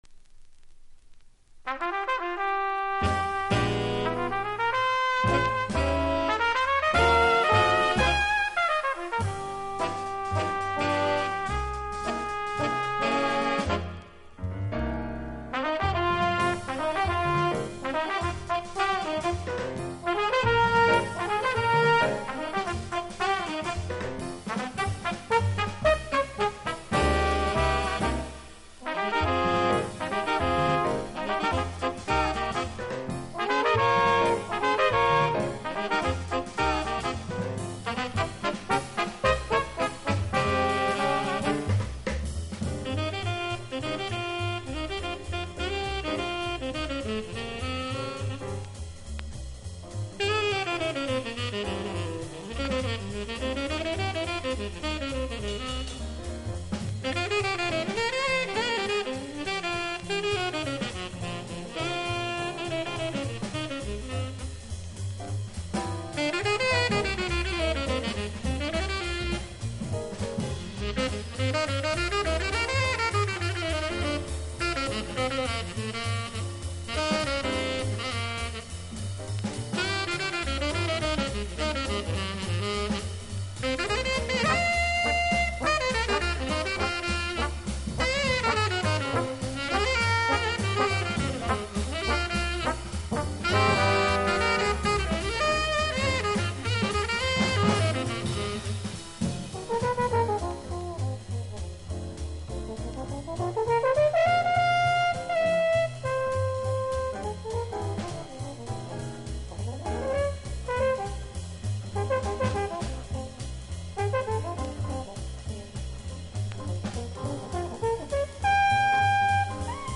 （小傷によりチリ、プチ音ある曲あ…